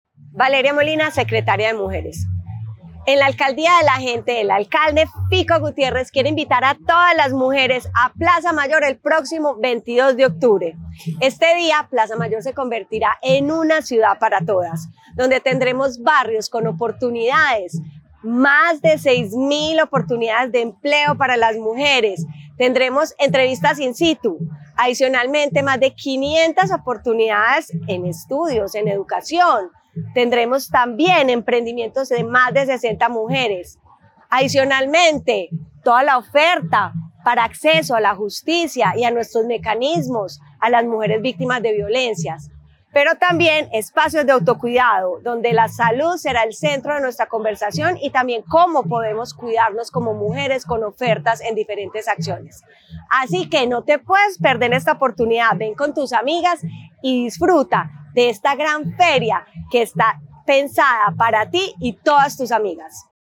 Declaraciones secretaria de las Mujeres, Valeria Molina
Declaraciones-secretaria-de-las-Mujeres-Valeria-Molina.mp3